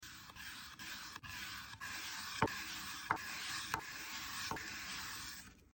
Cutting Thin Apple Slices Is Sound Effects Free Download